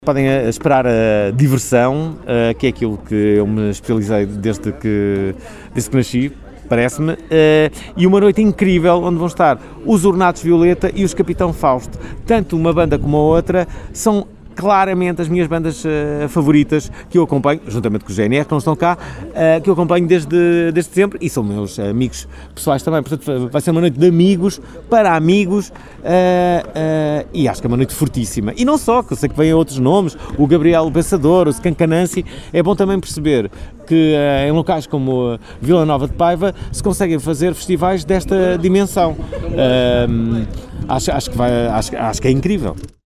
O Dj Fernando Alvim, que será o último a atuar na sexta-feira (29 agosto), em declarações exclusivas à Alive FM e em jeito de convite, diz que os festivaleiros podem esperar muita diversão.